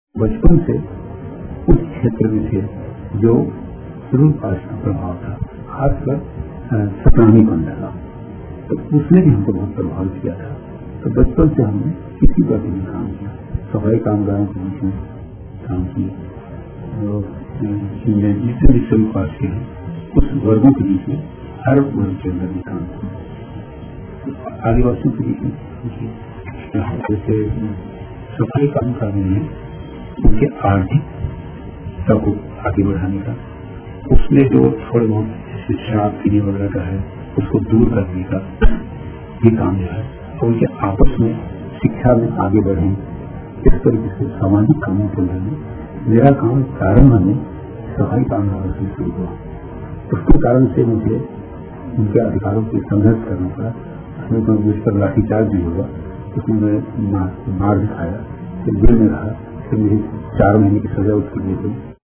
केयूर भूषण जी, स्वतंत्रता सेनानी, लेखक, समाज सुधारक, के साथ एक साक्षातकार-